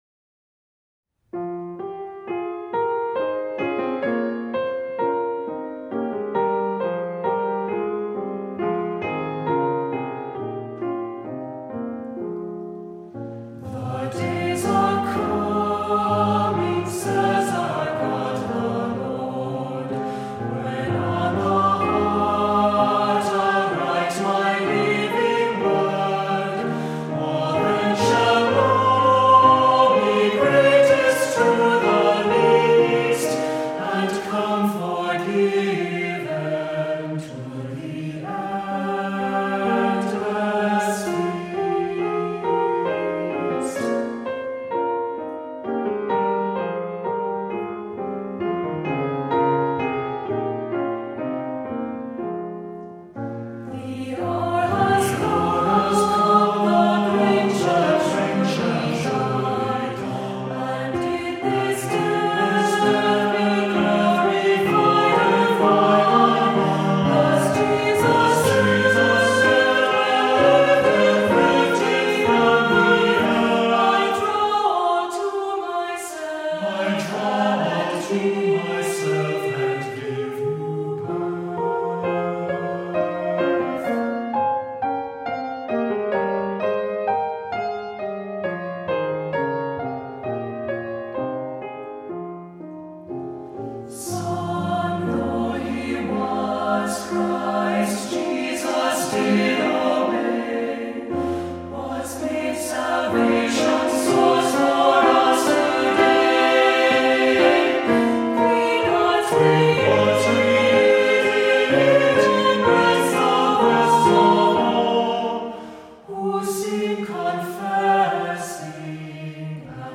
Voicing: "SAB"